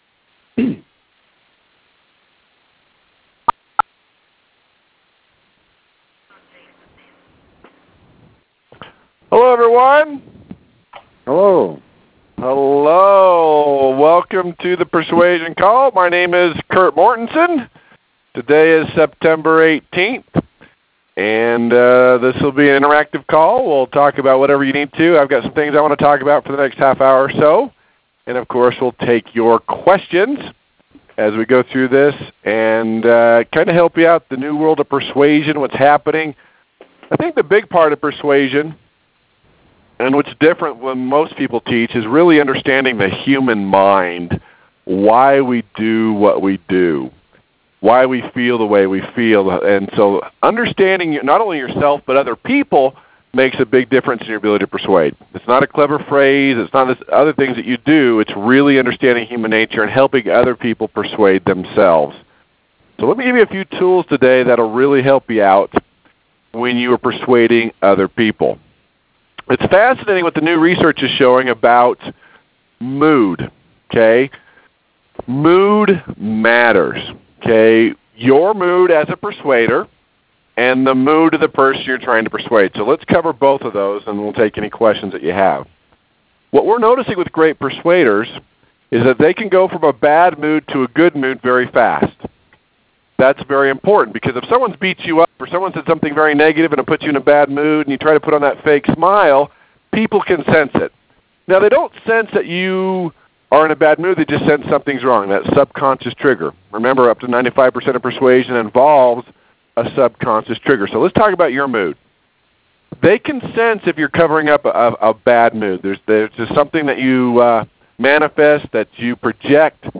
‹ Millionaire IQ Motivate to action › Posted in Conference Calls